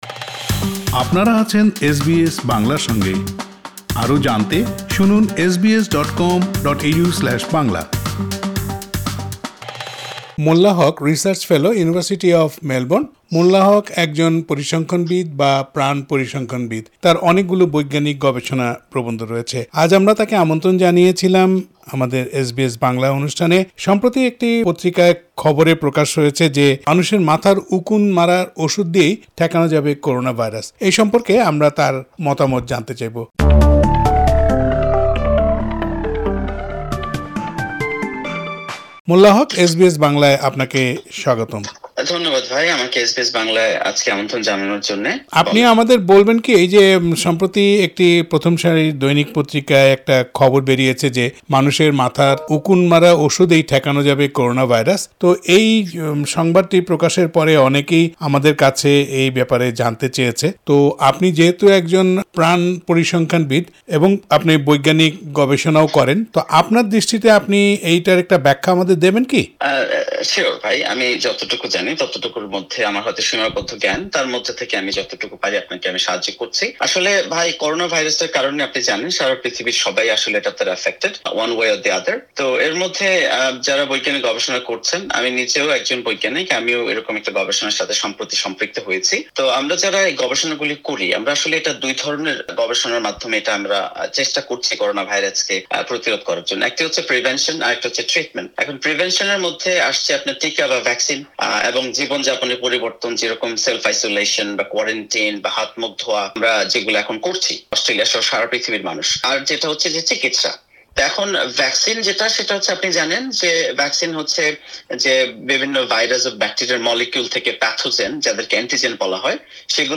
এ প্রসঙ্গে এস বি এস বাংলার সঙ্গে কথা বলেছেন তিনি।